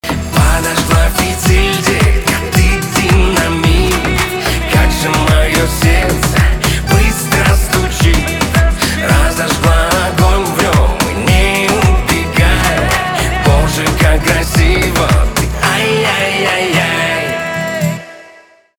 поп
романтические , чувственные , битовые , гитара